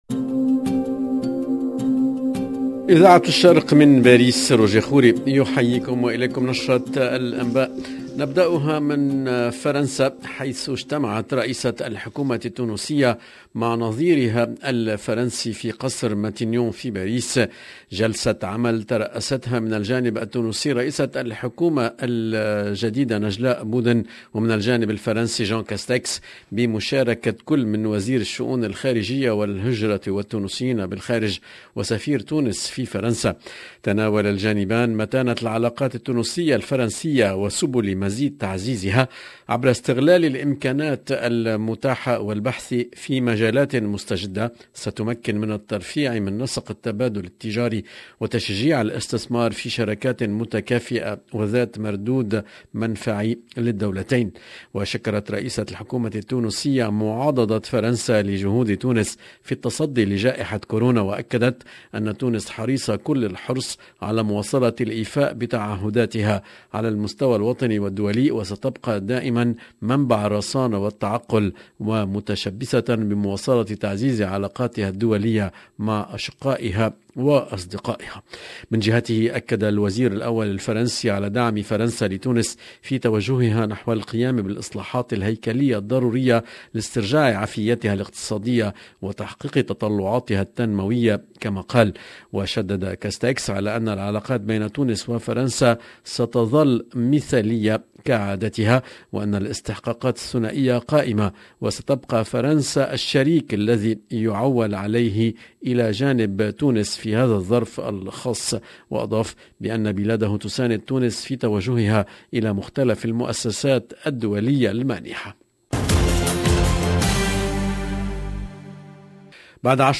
LE JOURNAL EN LANGUE ARABE DU SOIR DU 12/11/21